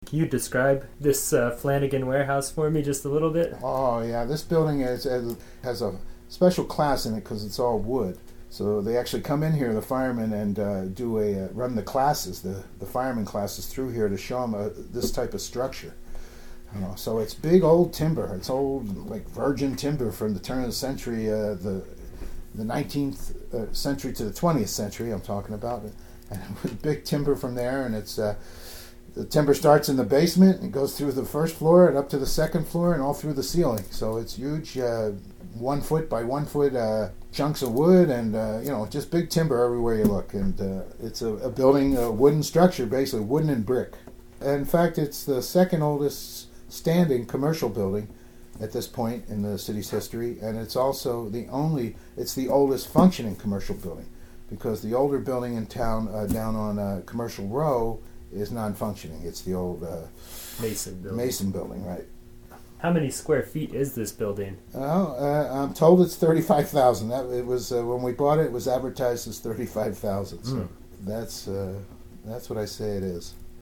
University of Nevada Oral History Program